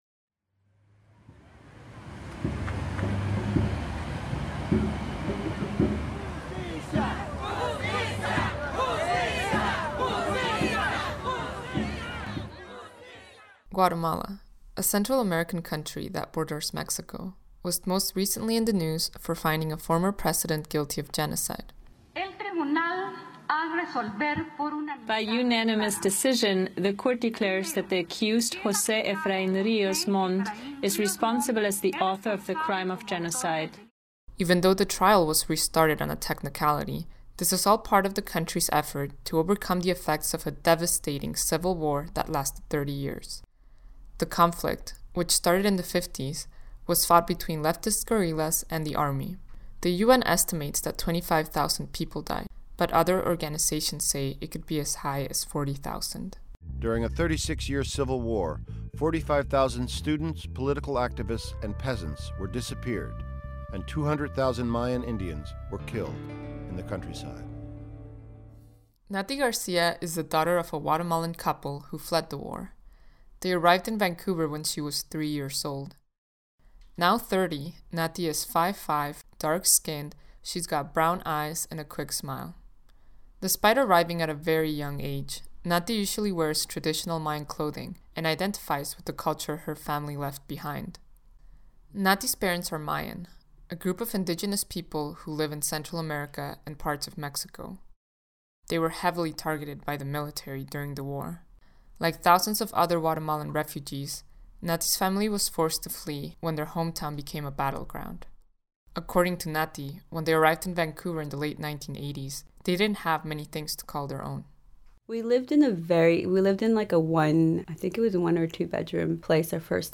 Radio_Documentary
Mono